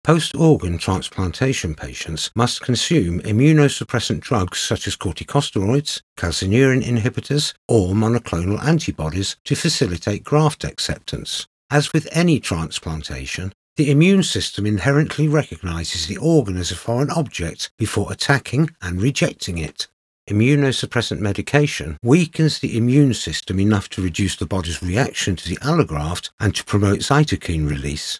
My natural voice is neutral British English, clear, friendly and easy to listen to with the ability to vary the dynamics to suit the project.
Explainer Videos
Words that describe my voice are Neutral British, Clear, Expressive.